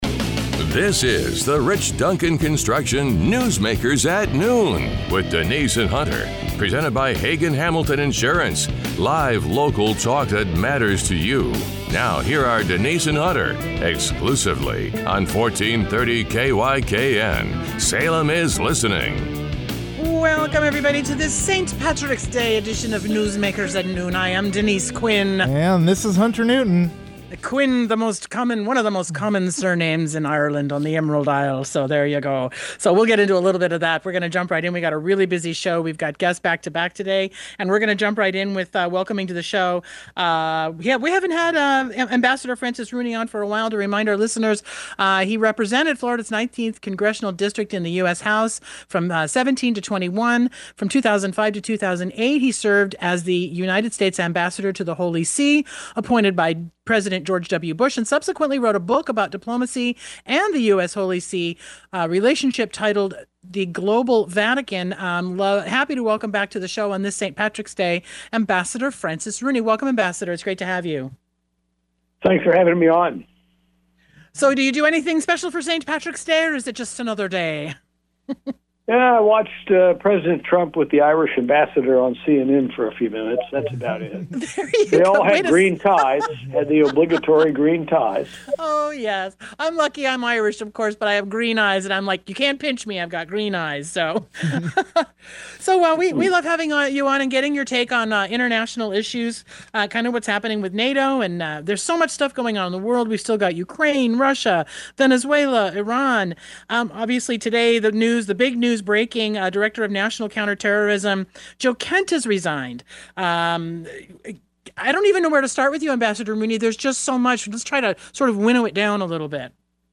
The show features a conversation with Ambassador Francis Rooney (former U.S. Ambassador to the Holy See and former Florida Congressman). Rooney discusses global issues ranging from NATO relations and European alliances to trade, China, Venezuela, Iran, and the ongoing Russia–Ukraine war.